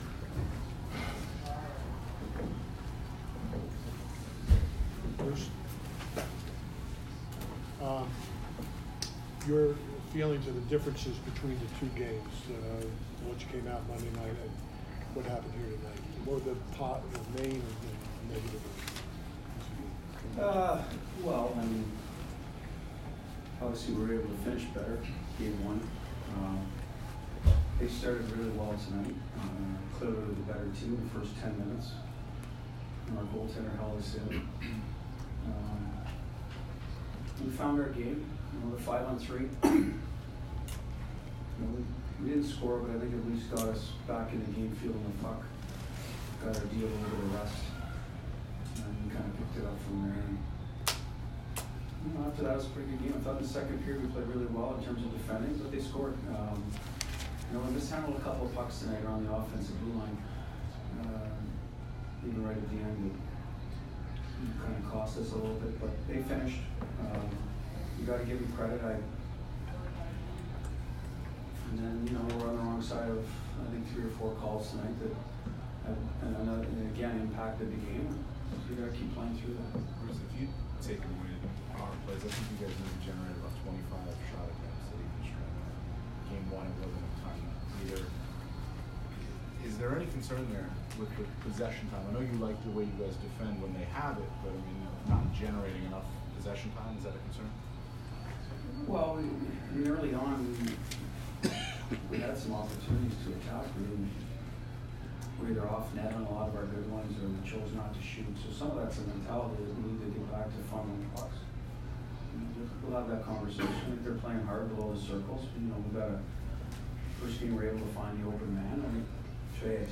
Bruce Cassidy post-game 4/30